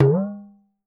02 TALKING D.wav